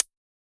UHH_ElectroHatC_Hit-12.wav